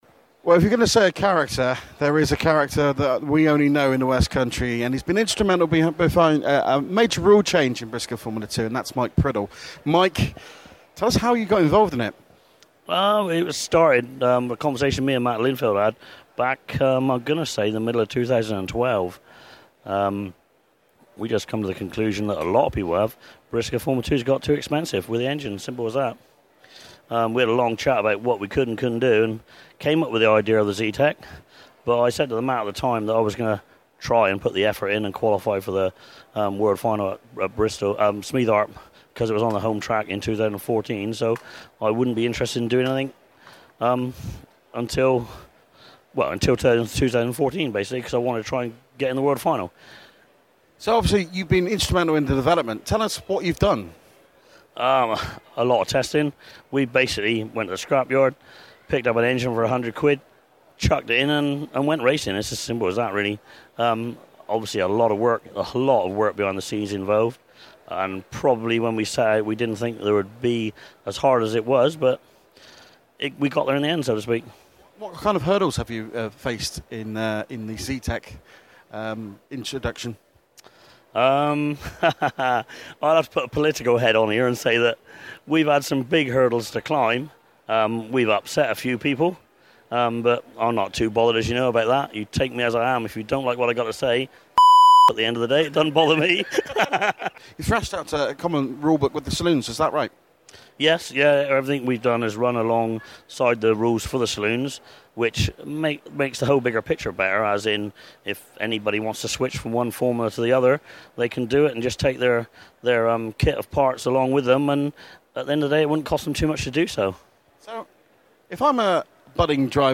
Autosport Show
Contains strong opinion!